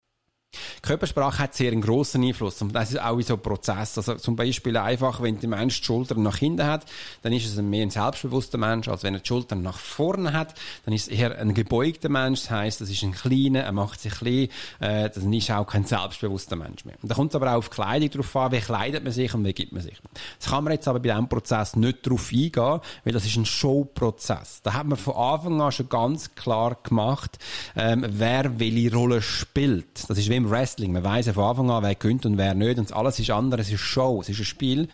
Dieses Interview gibt es auch auf Hochdeutsch!